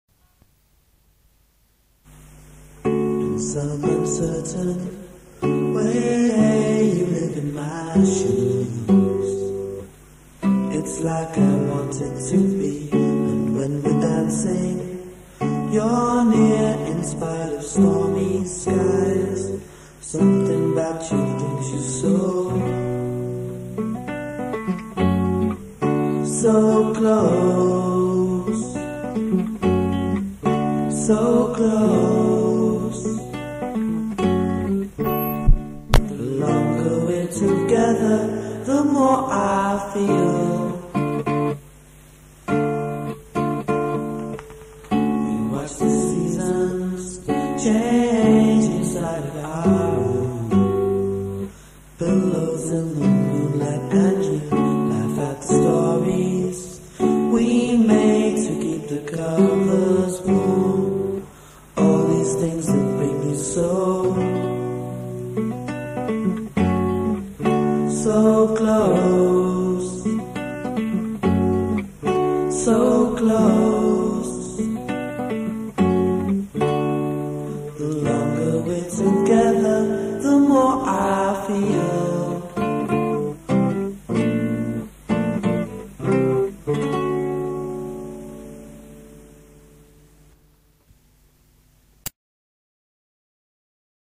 on a 4 track tascam over 20 years ago, both of us are singing. I liked it because it was the first song I’d recorded with major 7th chords and the words are nice.